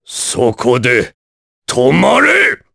Kaulah-Vox_Skill1_jp.wav